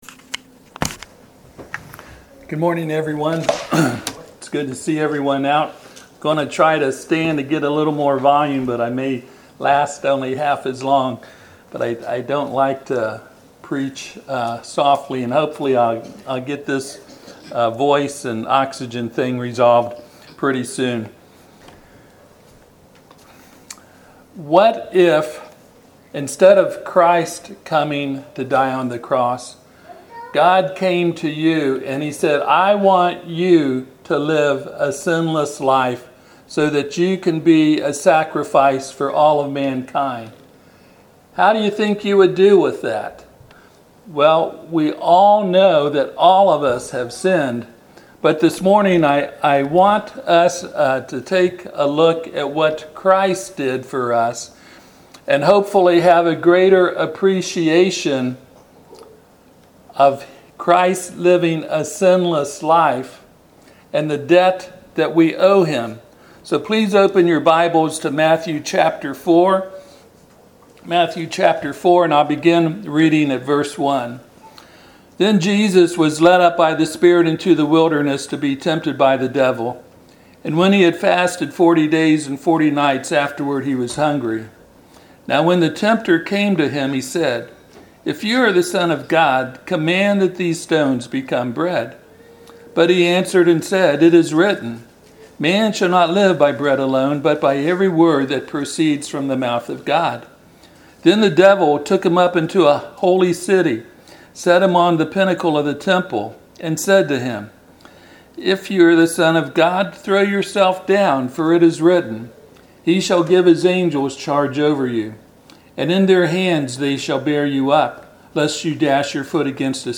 Passage: Matthew 4:1-11 Service Type: Sunday AM